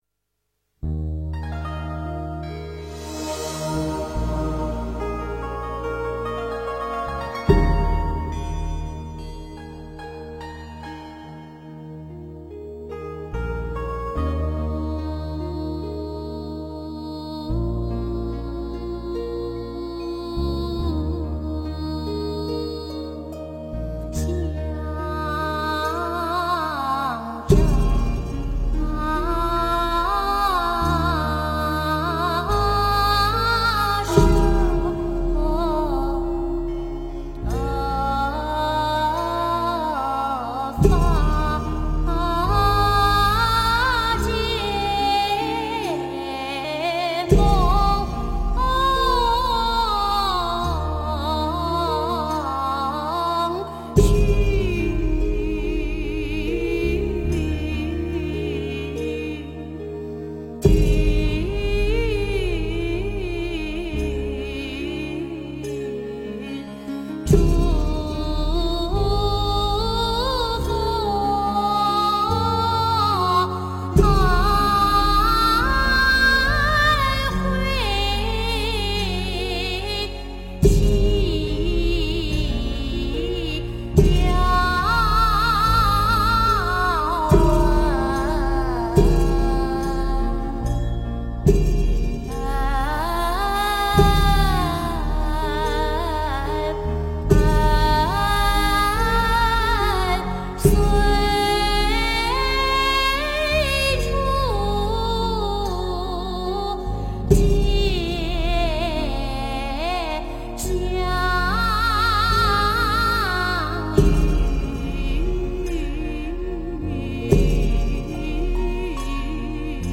标签: 佛音诵经佛教音乐